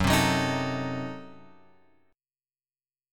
F# Diminished 7th